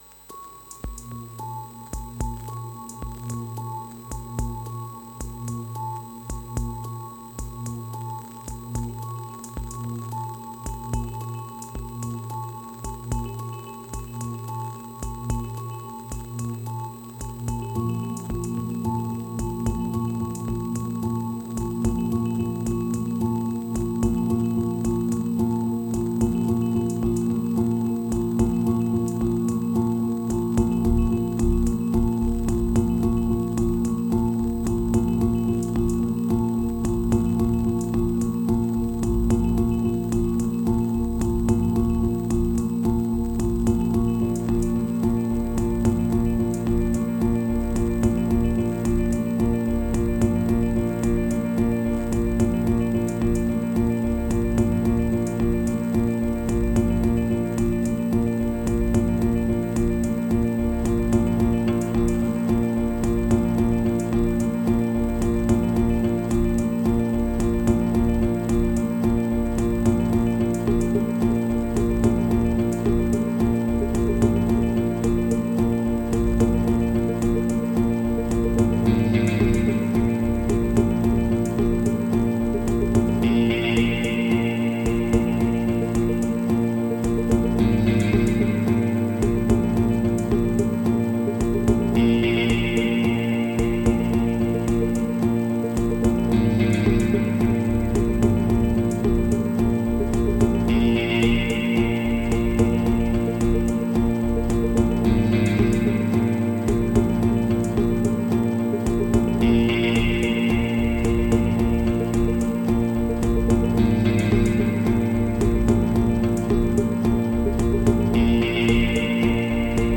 1575📈 - 96%🤔 - 55BPM🔊 - 2022-09-26📅 - 865🌟
Simple sample guitare rythmique.